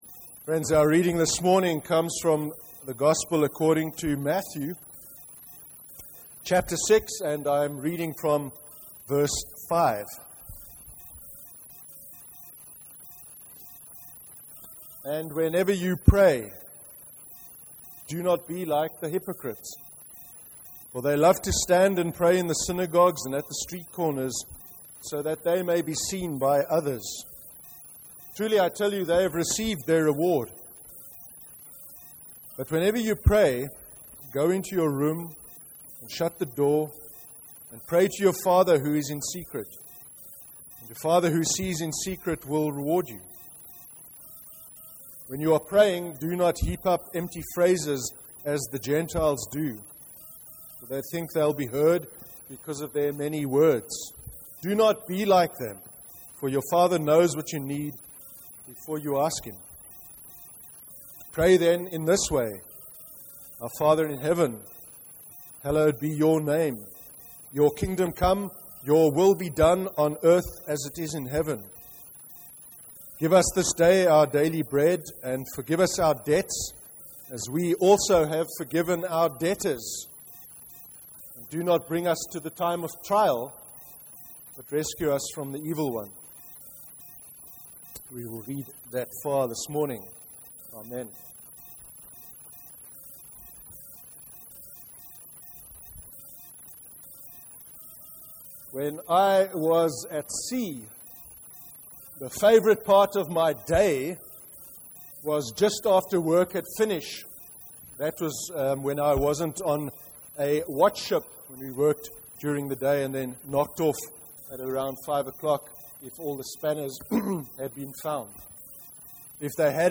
23/11/2014 sermon: Contemplative prayer (Matthew 6:5-13)